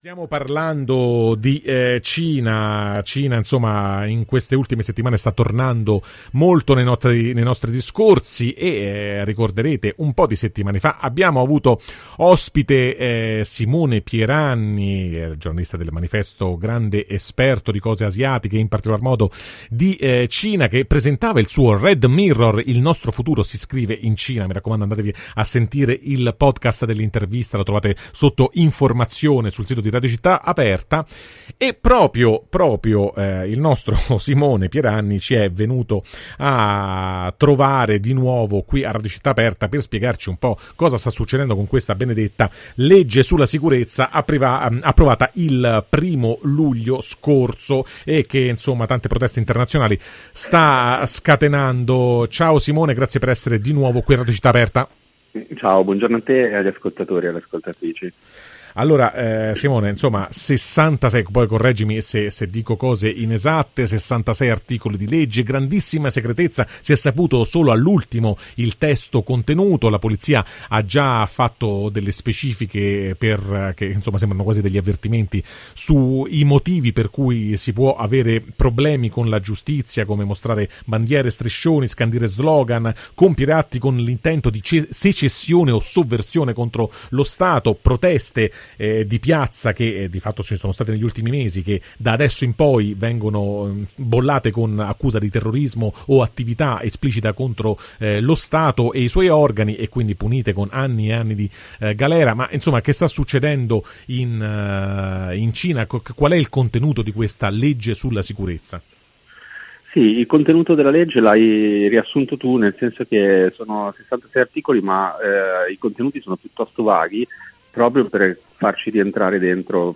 “Un paese, Un Sistema”: come la Cina sta risolvendo i suoi problemi con Hong Kong [Intervista